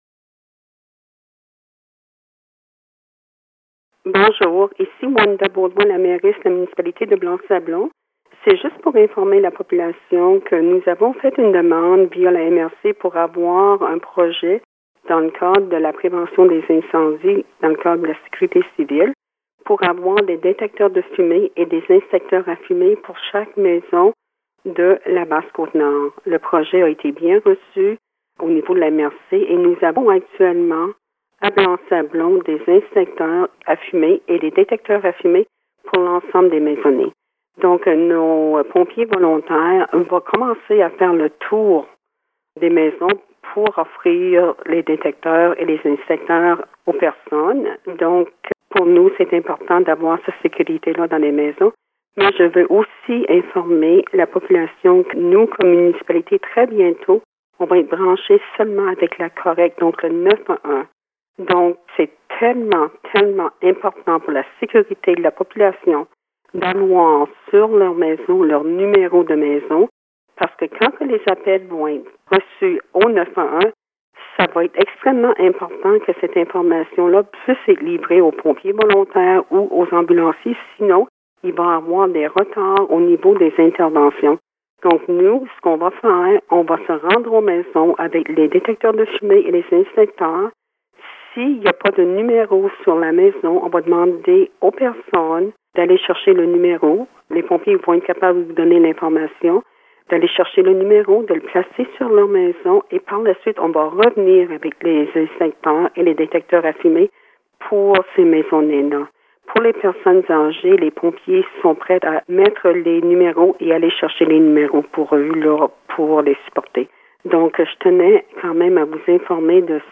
An interview update from the Mayor of the Municipality of Blanc-Sablon, Wanda Beaudoin, regarding: